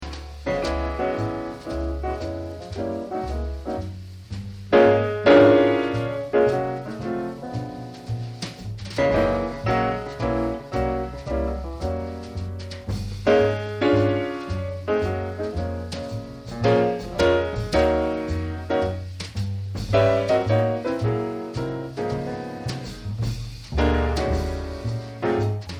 basse
batterie
CHICAGO